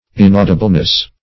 In*au"di*ble*ness, n. -- In*au"di*bly, adv.